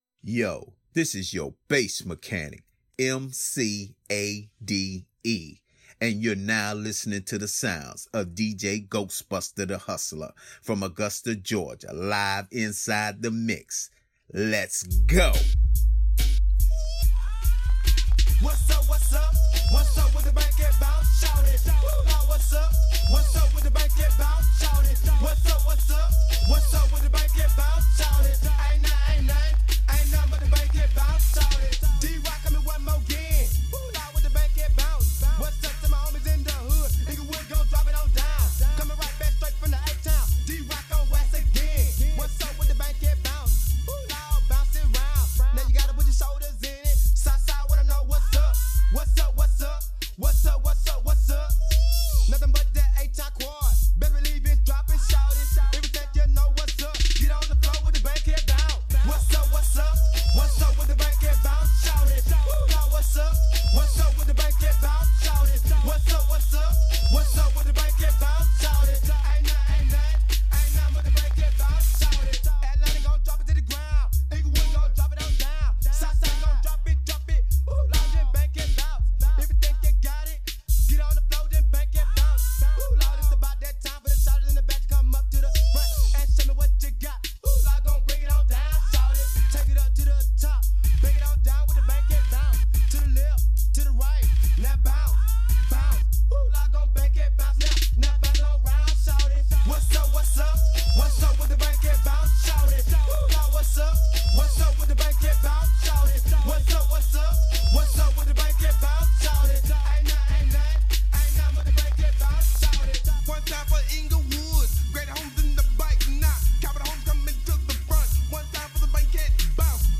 Hiphop
Minin Bass Mix